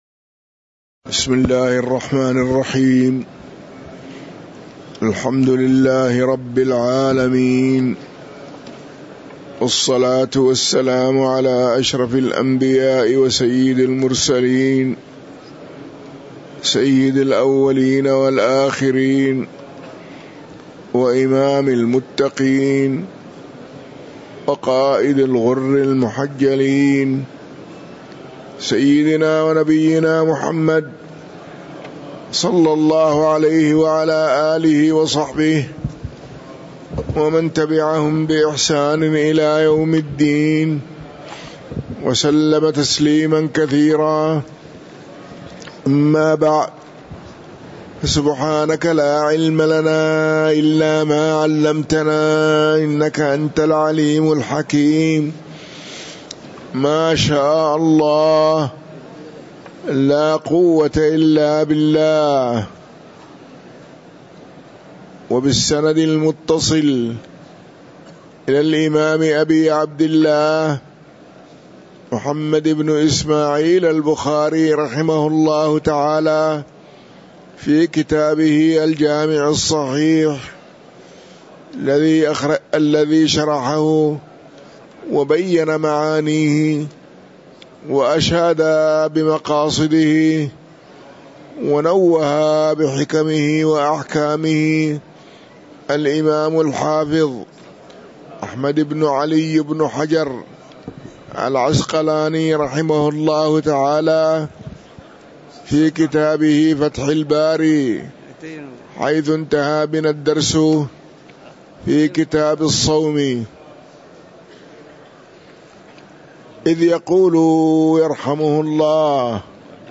تاريخ النشر ١٠ ذو القعدة ١٤٤٤ هـ المكان: المسجد النبوي الشيخ